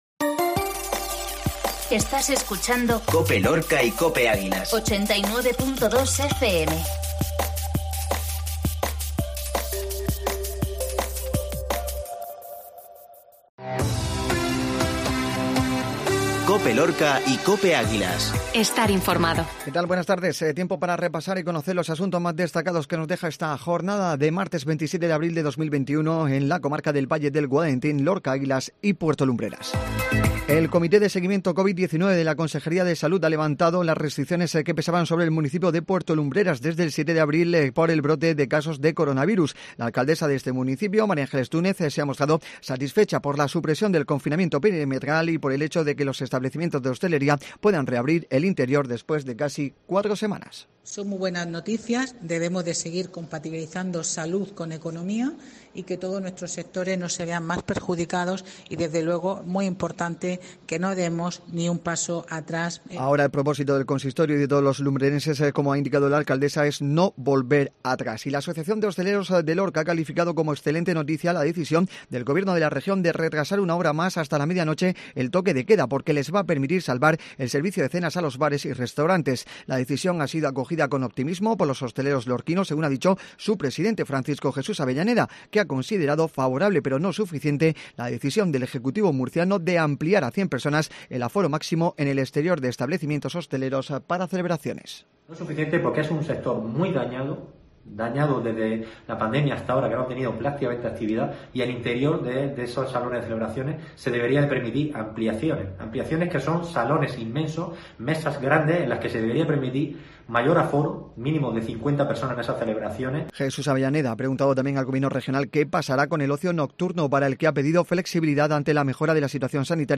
INFORMATIVO MEDIODÍA MARTES